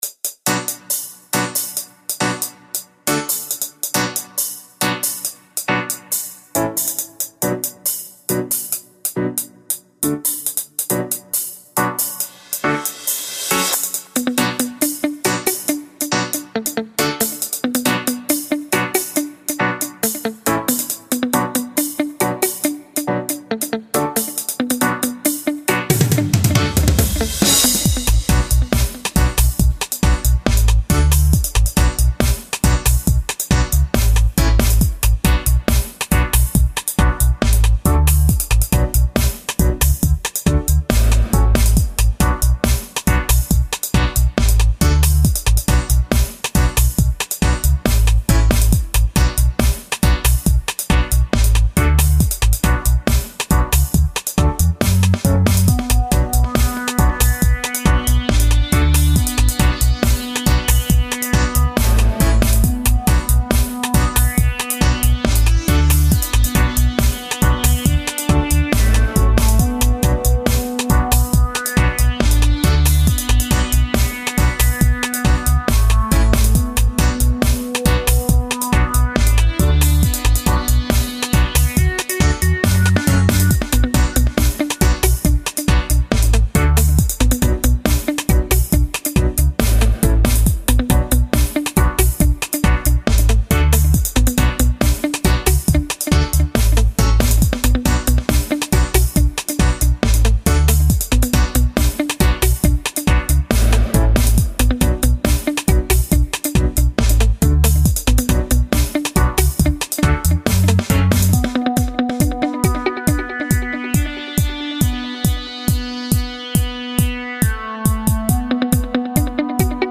older's crew. contact me if you want proper wave version to play on sound system. i'm looking for lyrics so ... let's sing on it my bredreen and sistreen !!!! bless !